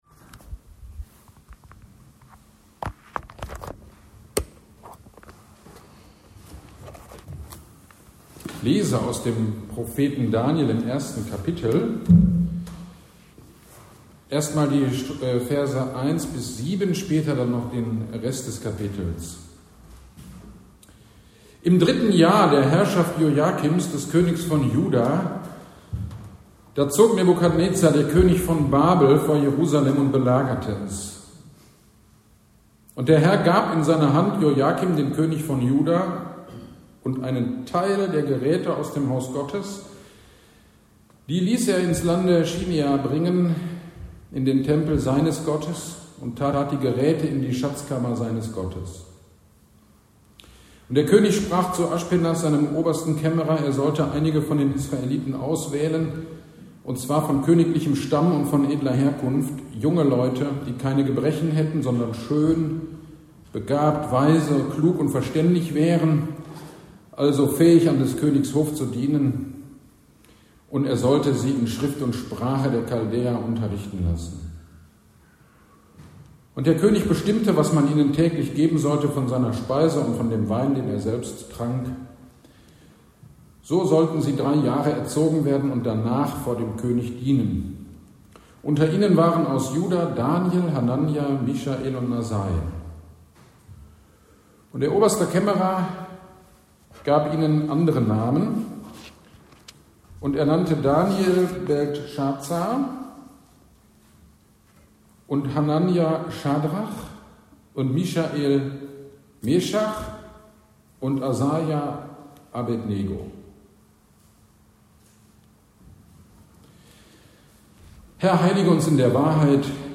GD am 18.08.2024 Predigt zu Daniel 1 - Kirchgemeinde Pölzig